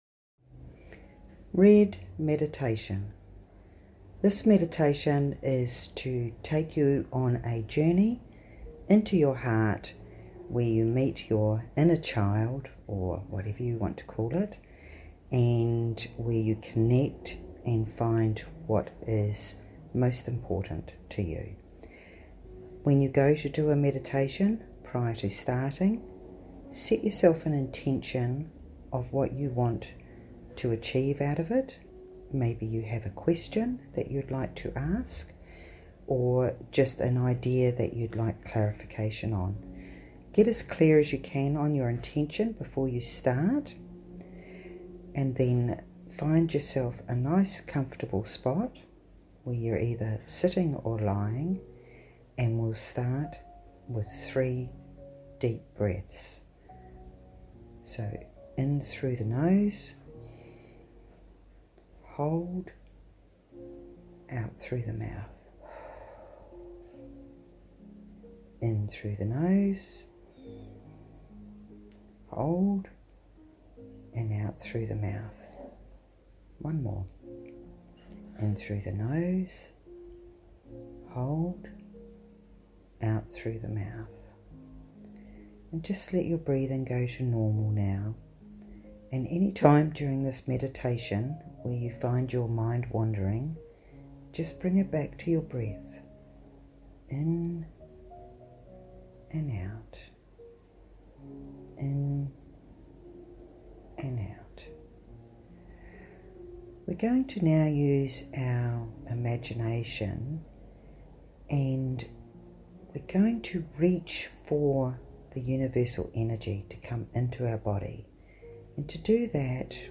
RED Meditation with Music.mp3